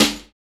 Index of /90_sSampleCDs/Roland - Rhythm Section/SNR_Snares 7/SNR_Sn Modules 7